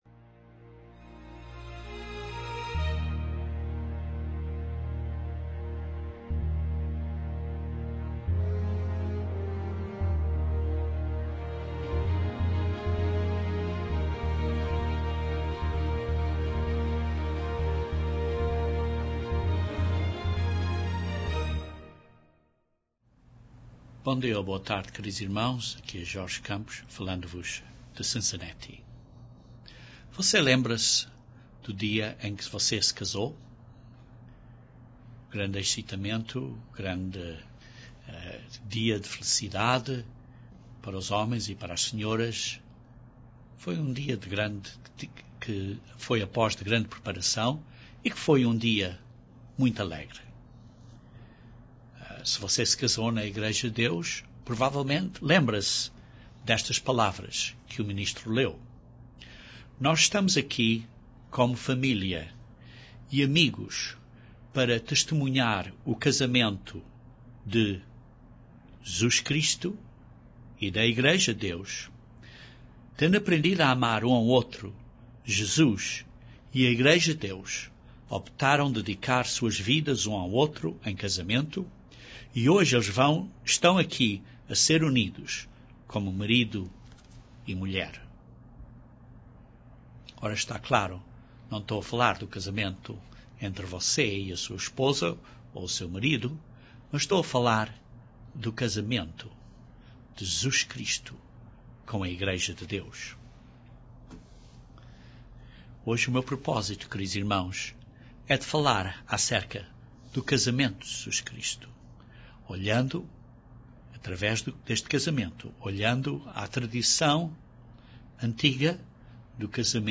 Usando 5 passos tradicionais do matrimónio de Israel da antiquidade, este sermão compara esses passos com princípios esprituais antes da boda do Cordeiro.